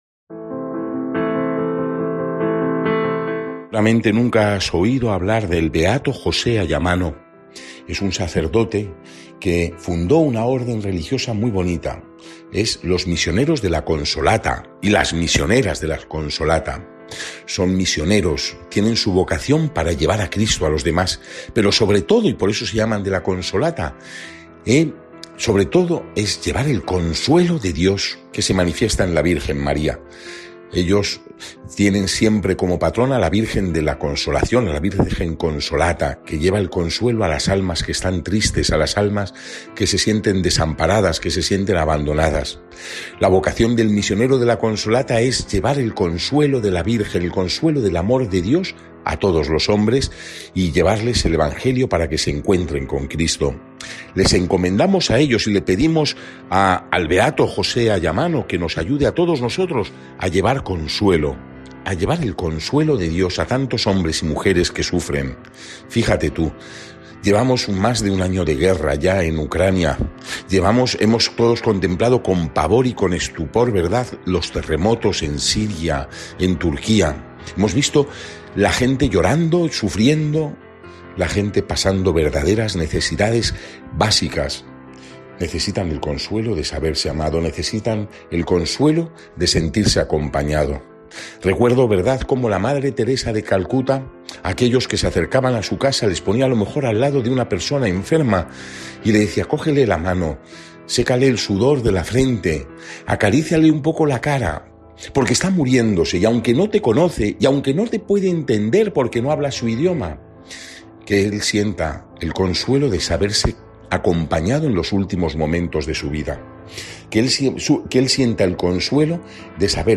Lectura del santo evangelio según san Marcos 8,27-33